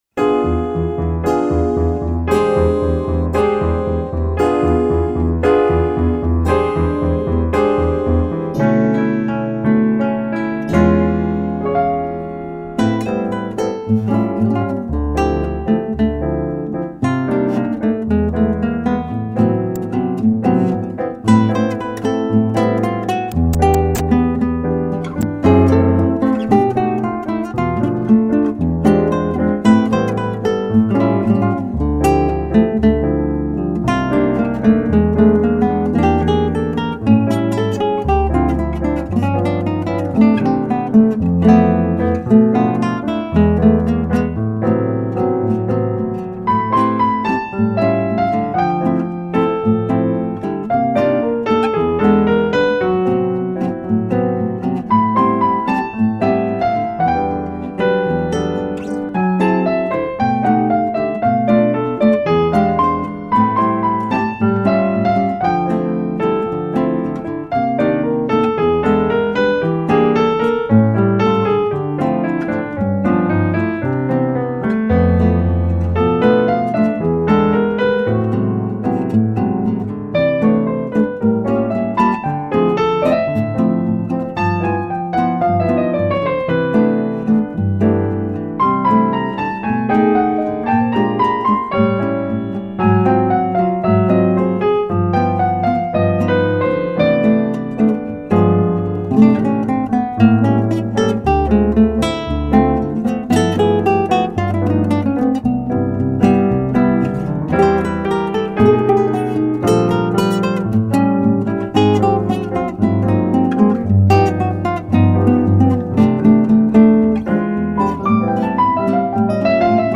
1359   04:34:00   Faixa:     Jazz
Violao Acústico 6
Teclados